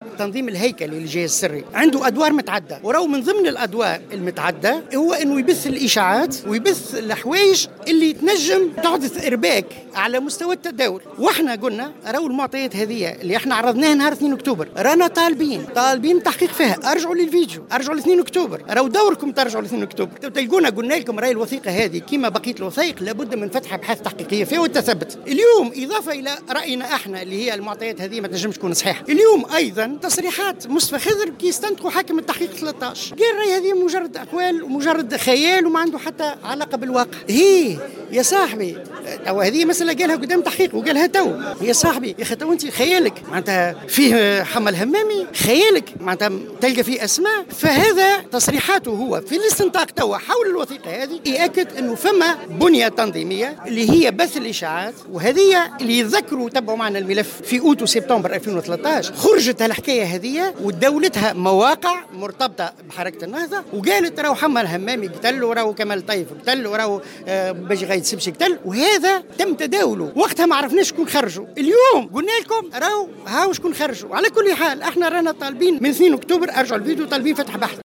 وأوضح في تصريح لمراسلة "الجوهرة اف أم" على هامش ندوة صحفية اليوم في الحمامات، أن من بين أدوار التنظيم الهيكلي للجهاز السري بث الإشاعات وإحداث الارباك.